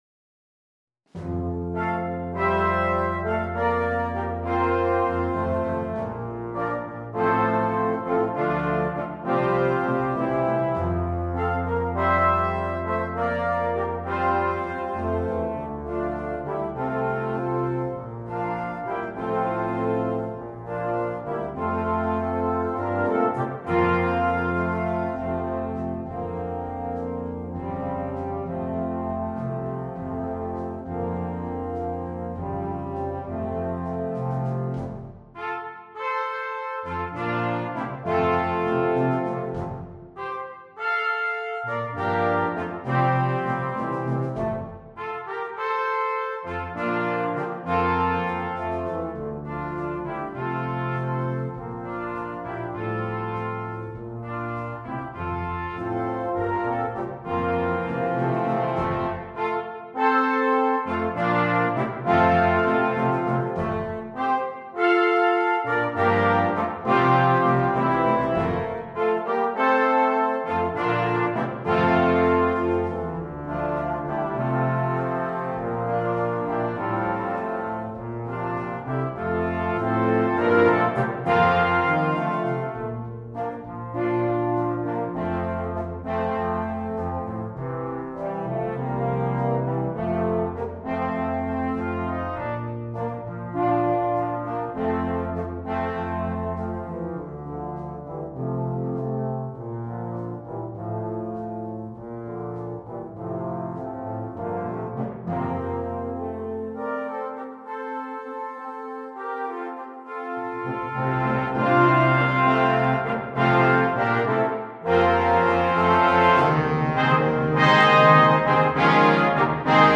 2. Brass Band
Full Band
without solo instrument
Entertainment
Music Sample